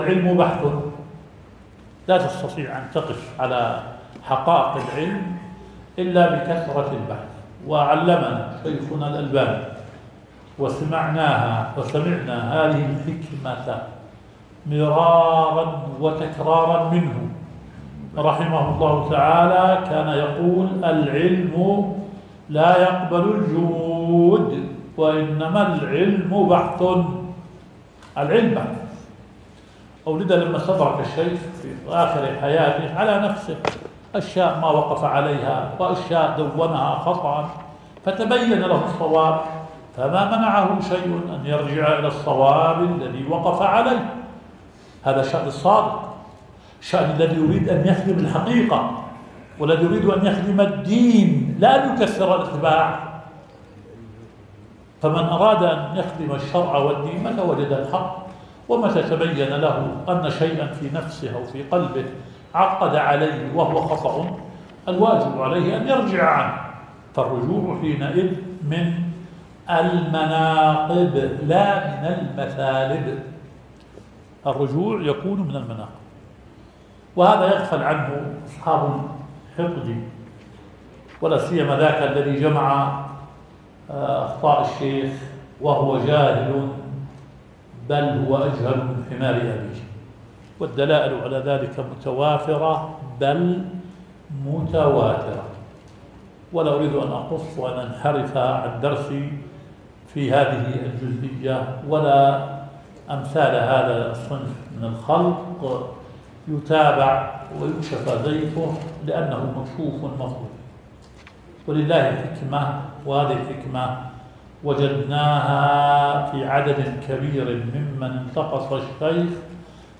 البث المباشر – لدرس شيخنا شرح صحيح مسلم – فضيلة الشيخ مشهور بن حسن آل سلمان.